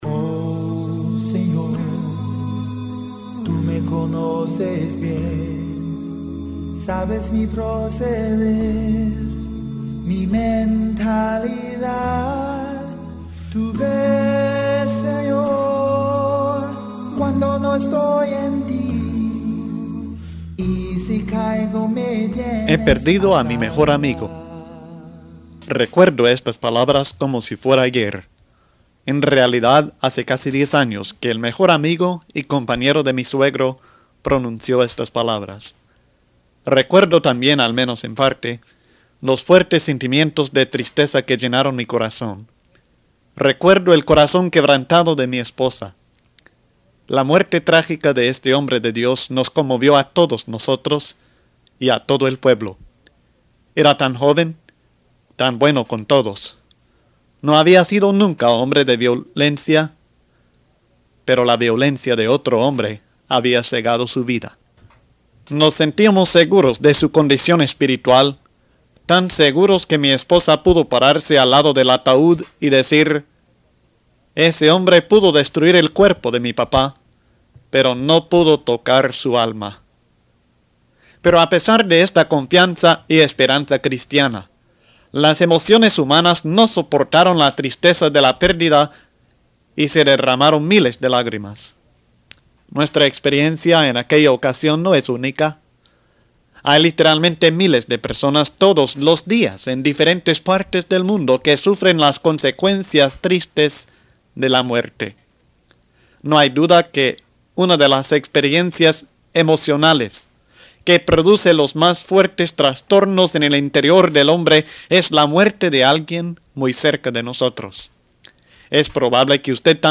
🔊 Estudie la palabra de Dios, escuchando el mensaje de cada link en los temas expuestos por el predicador.